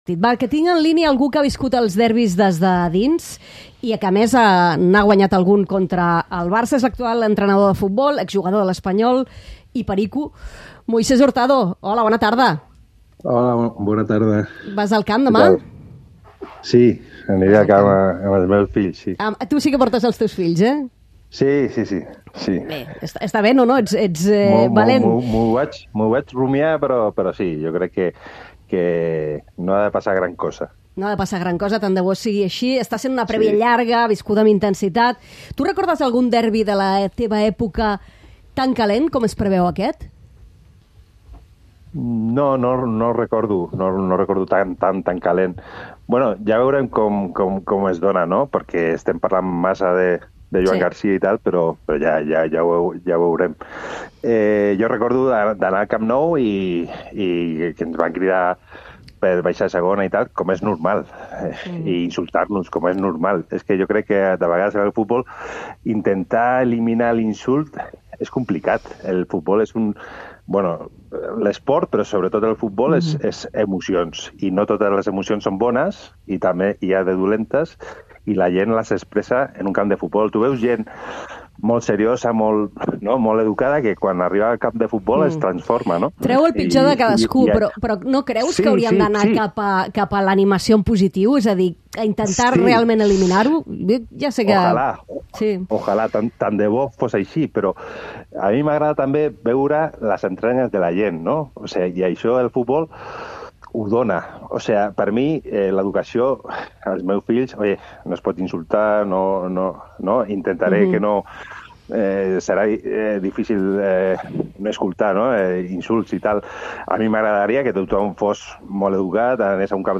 Entrevistem l'exjugador de l'Espanyol Moisés Hurtado. Amb ell, comentem l'ambient que es preveu al derbi amb la tornada de Joan Garcia a l'estadi blanc-i-blau.